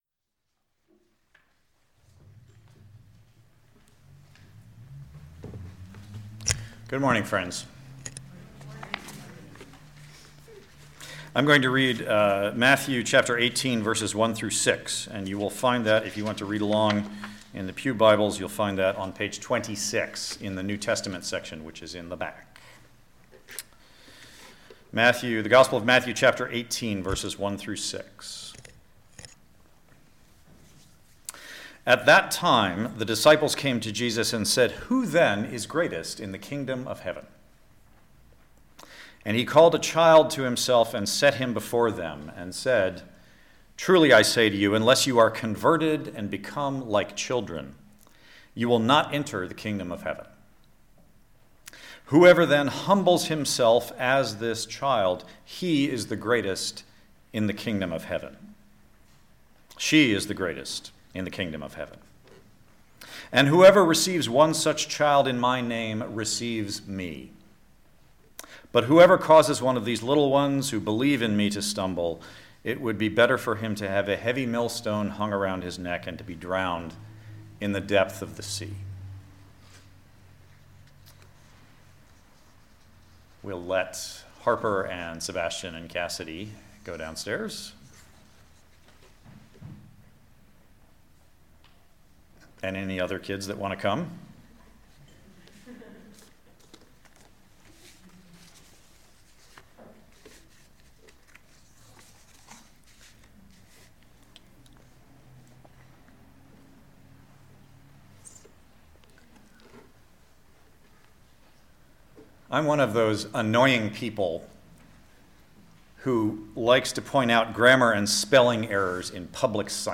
Listen to the most recent message from Sunday worship at Berkeley Friends Church.